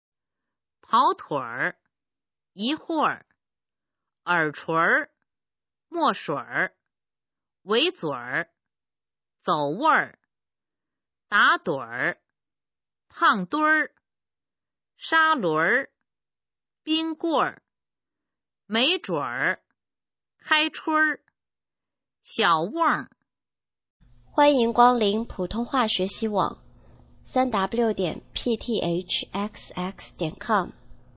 普通话水平测试用儿化词语表示范读音第11部分